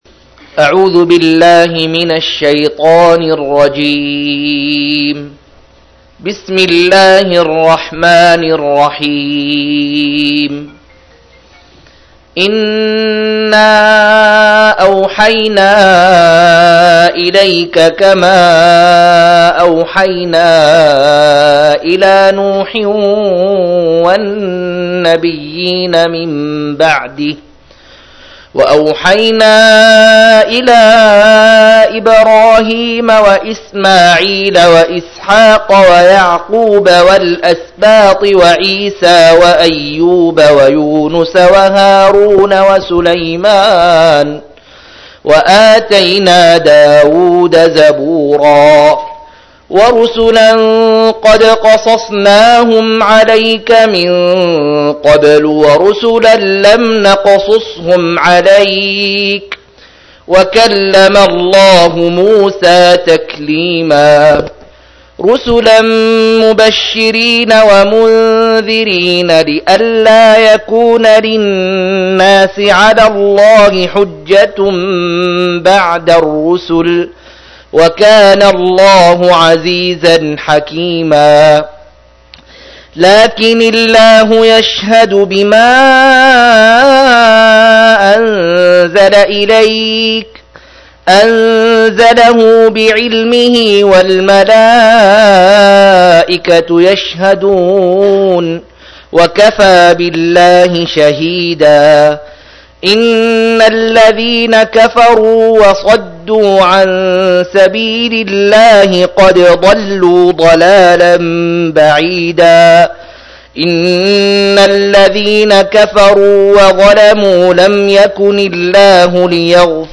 105- عمدة التفسير عن الحافظ ابن كثير رحمه الله للعلامة أحمد شاكر رحمه الله – قراءة وتعليق –